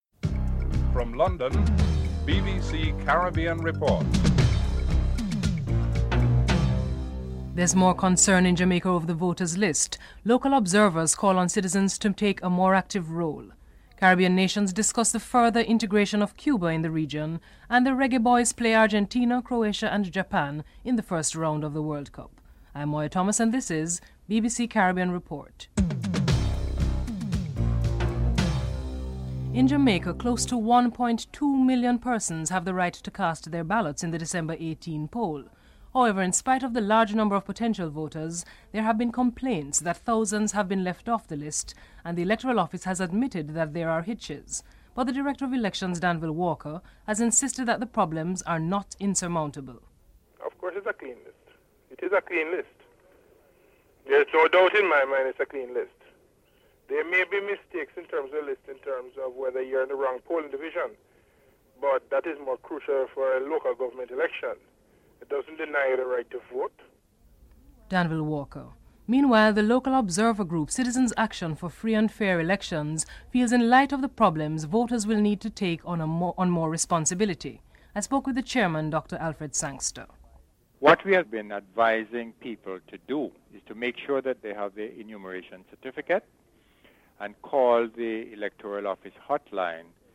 3. Caribbean nations discuss the further integration of China in the region. Caricom Chief Negotiator Sir Shridath Ramphal and Cuba Vice-President Carlos Lage are interviewed.